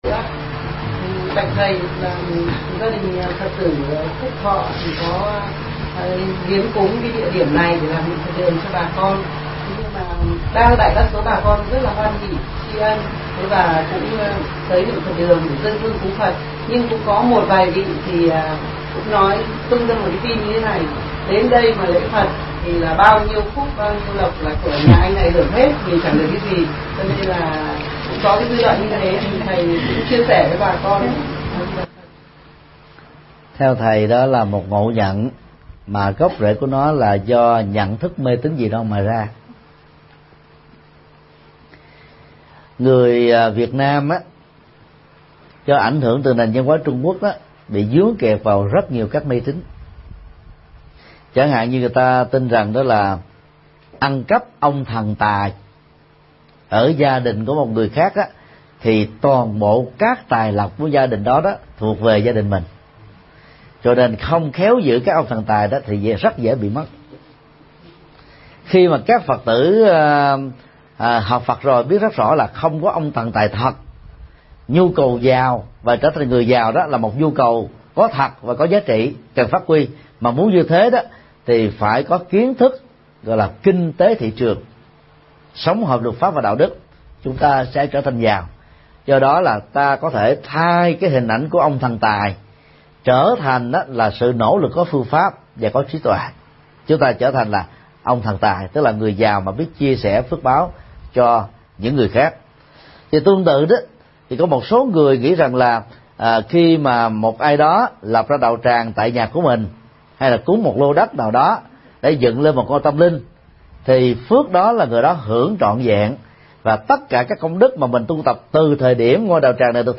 Vấn đáp: Phước báu lễ Phật – Thích Nhật Từ